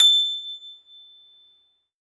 53s-pno24-A5.aif